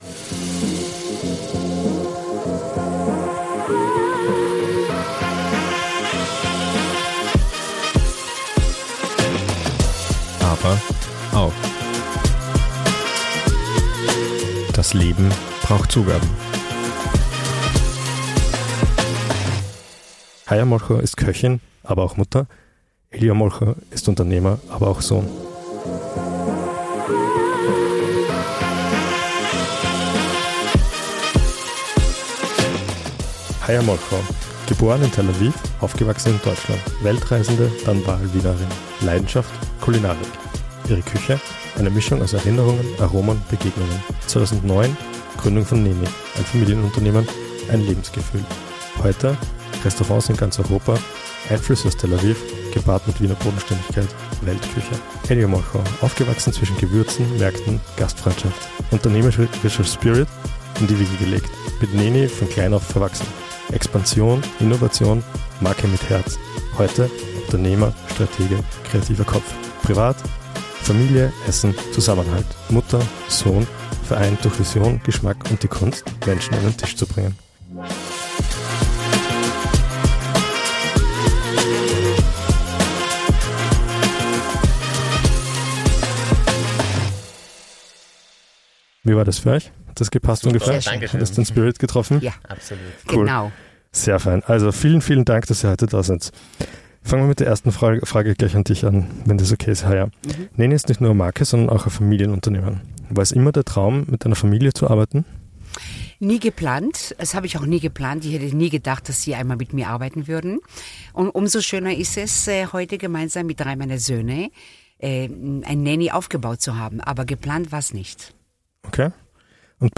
Wir feiern mit unserem Podcast eine kleine Premiere, durften wir dieses Mal doch gleich zwei Personen interviewen.